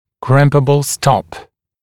[‘krɪmpəbl stɔp][‘кримпэбл стоп]стопор, фиксирующийся на дуге путем обжимания его опорной части специальным инструментом